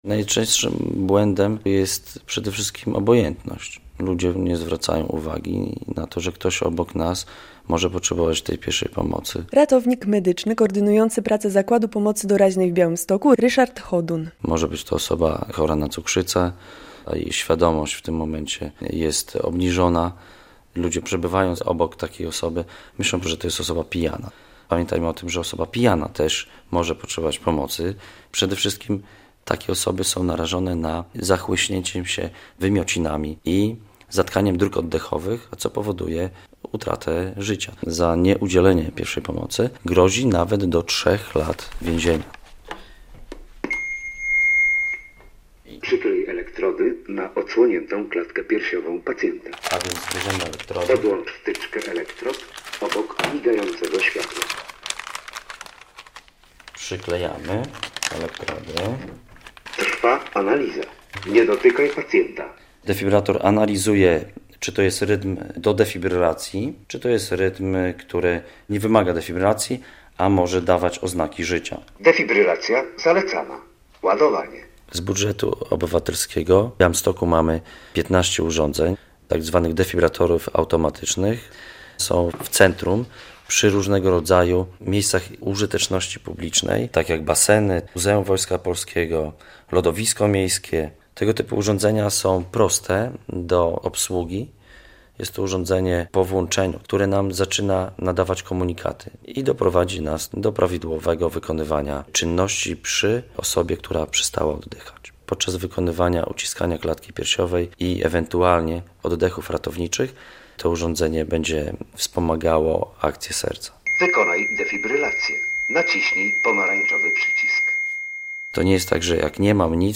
Najczęstsze błędy przy udzielaniu pierwszej pomocy - relacja